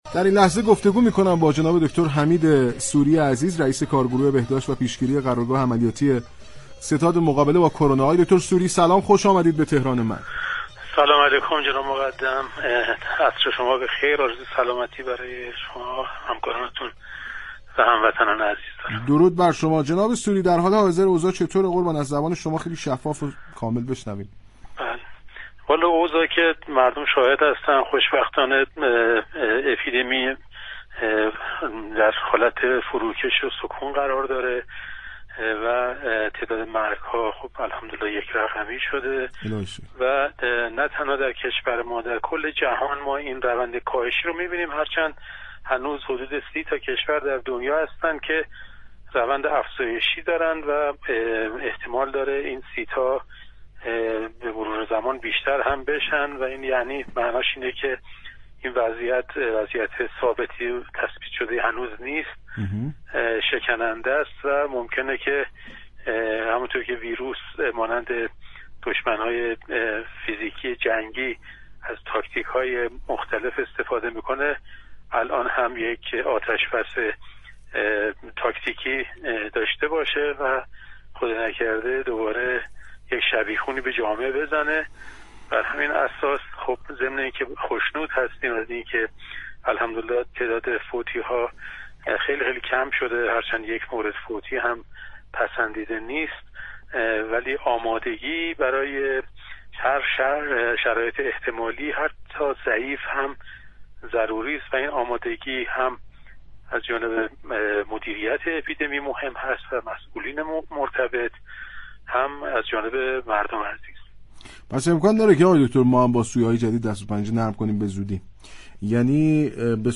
به گزارش پایگاه اطلاع رسانی رادیو تهران، دكتر حمید سوری رئیس كارگروه بهداشت و پیشگیری قرارگاه عملیاتی ستاد مقابله با كرونا در گفت و گو با برنامه تهران من رادیو تهران درباره وضعیت اپیدمی كرونا در ایران گفت: خوشبختانه اپیدمی در حالت كاهش و سكون است و مرگ و میر تك‌رقمی شده است.